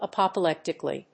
音節àp・o・pléc・ti・cal・ly 発音記号・読み方
/‐kəli(米国英語)/